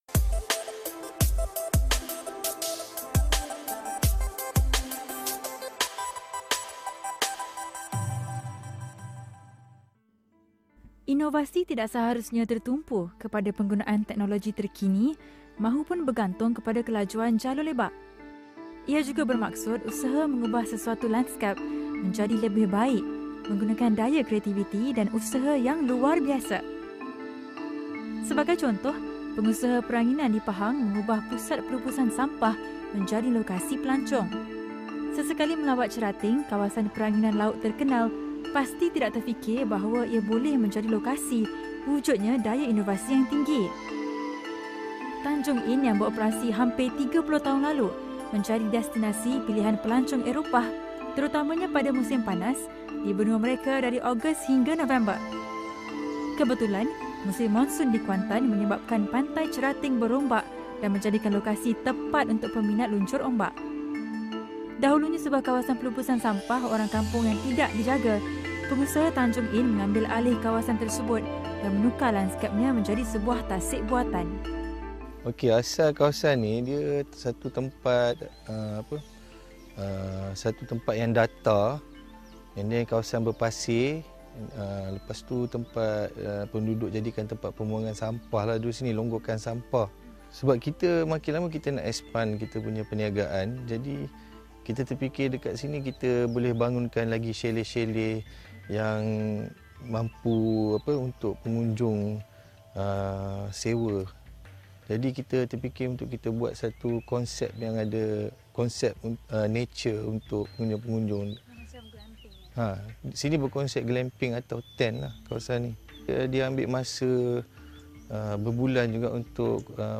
menemubual